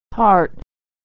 Lessons about authentic use and pronunciation of American English
Consonant Sound Voiced r = /ər/
heart.mp3